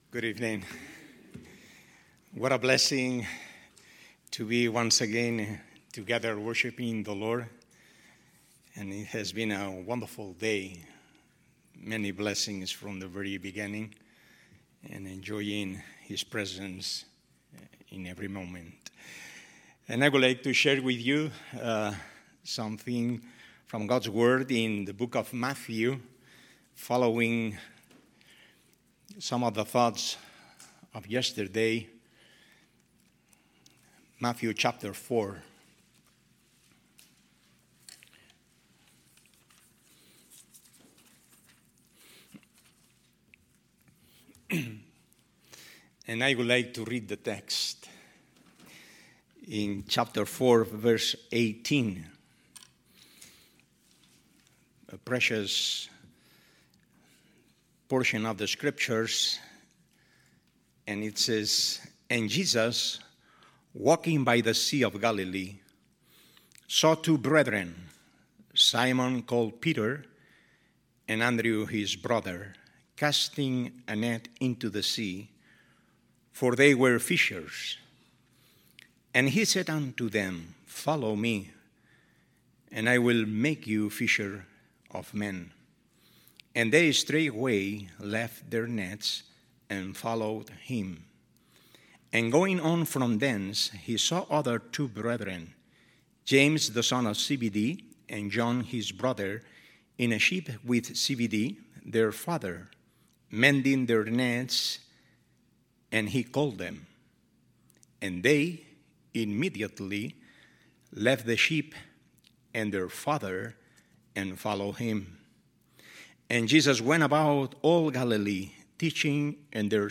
Missions Conference , Sermons